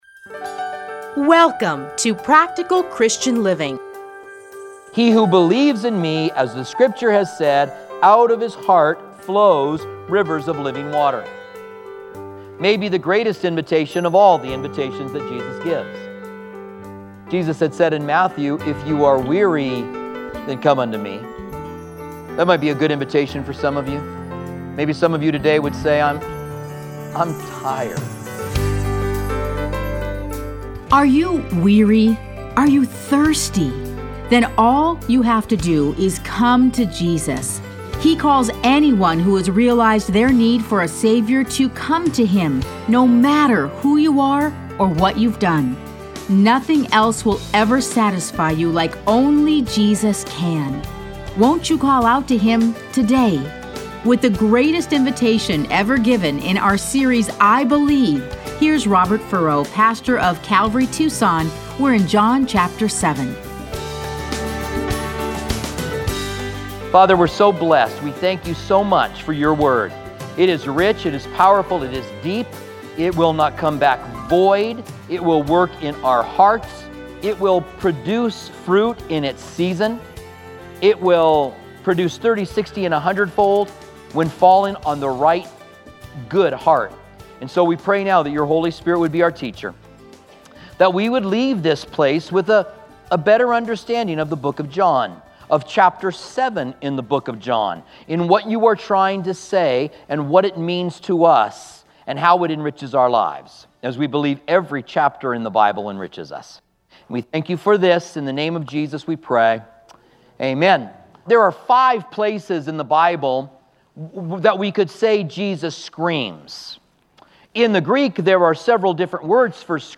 Listen to a teaching from John 7:32-53.